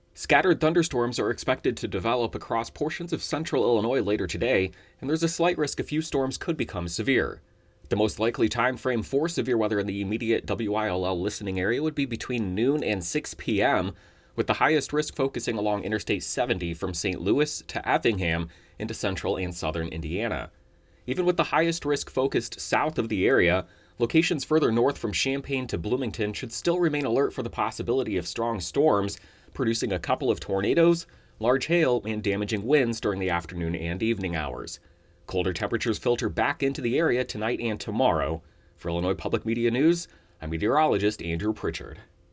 Locally damaging wind gusts and a few tornadoes are the primary concerns, although isolated large hail is possible. The forecast